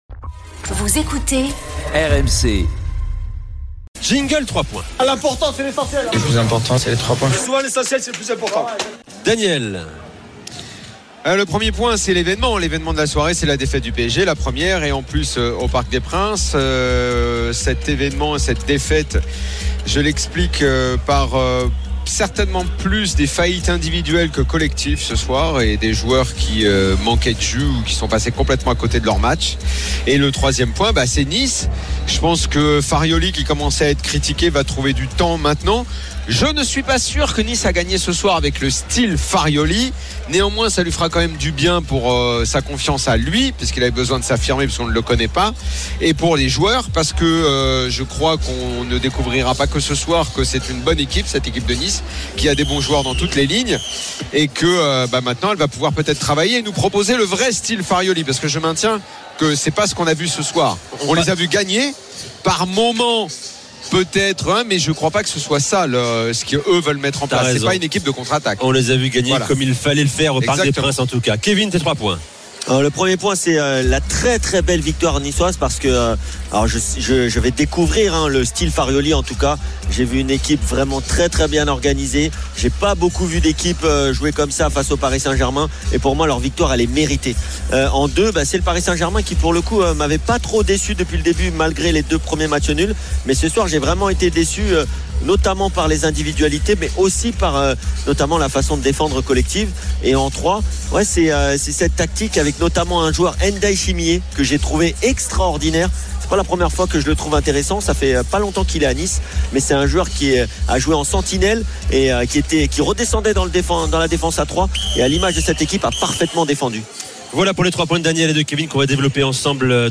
Chaque jour, écoutez le Best-of de l'Afterfoot, sur RMC la radio du Sport !